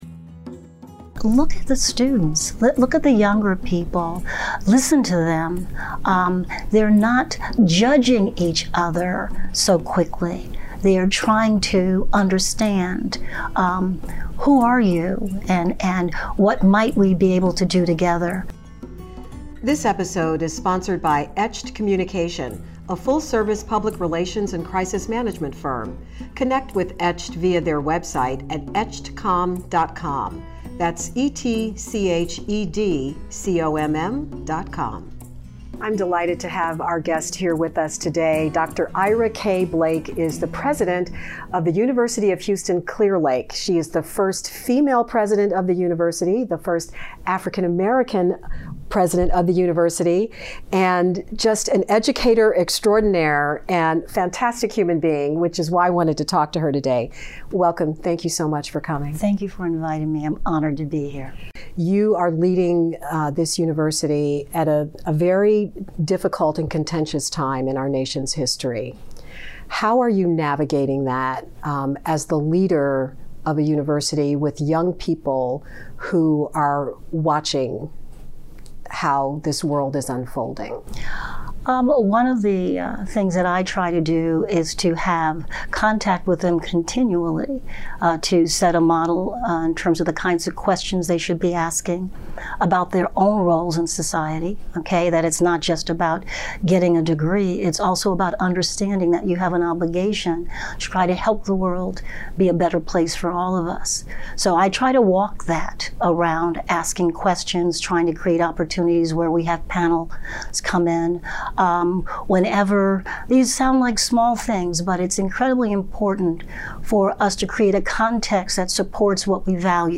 She’s a soft-spoken powerhouse